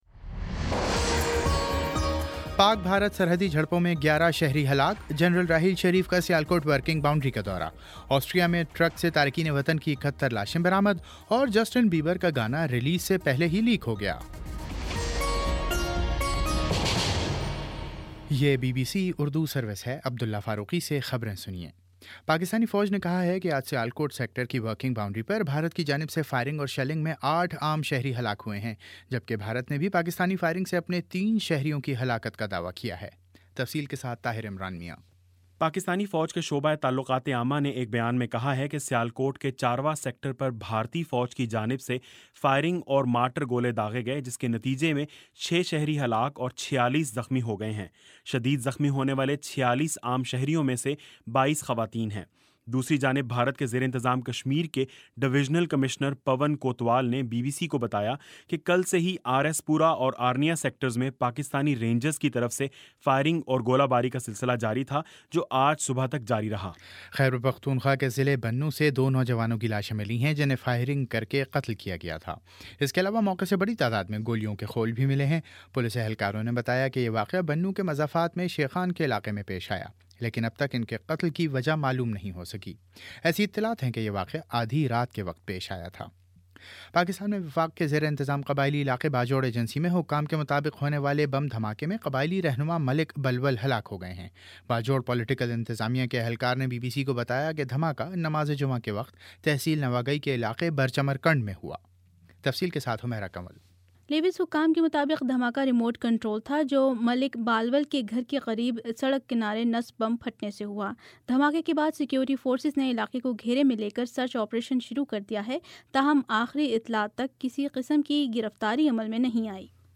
اگست 28: شام سات بجے کا نیوز بُلیٹن